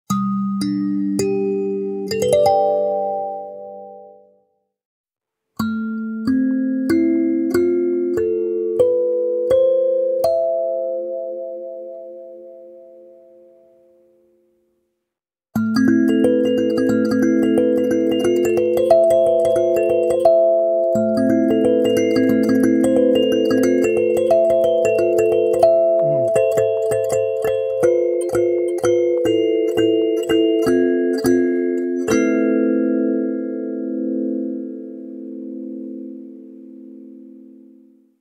Sansula Basic • La Mineur
Grâce à sa membrane amplificatrice, elle produit un son doux, flottant et aérien, idéal pour la méditation, la relaxation et la sonothérapie.
• 9 lamelles accordées en La Mineur, pour une harmonie fluide et intuitive
• Membrane amplificatrice, offrant un son profond et vibratoire
• Effet sonore en modulant la pression sur la base de l’instrument
Sansula-Basic-La-mineur.mp3